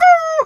chicken_2_bwak_hurt_06.wav